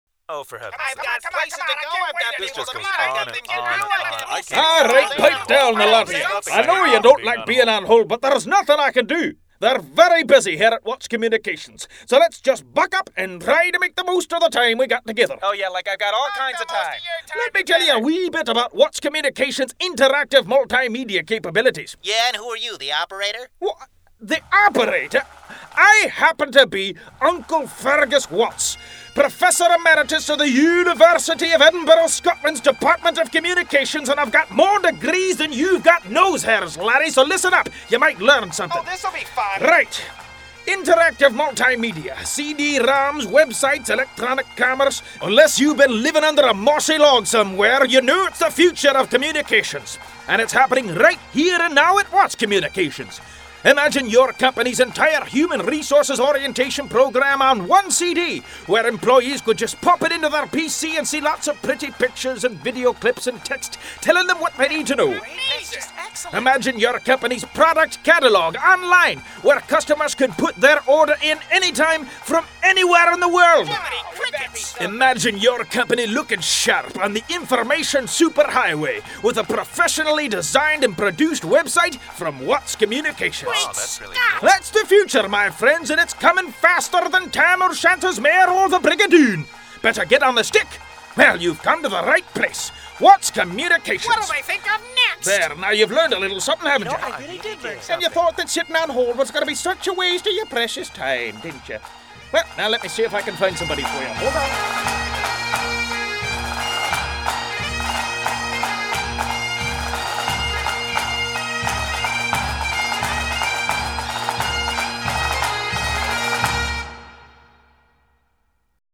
Uncle Fergus On-Hold Messaging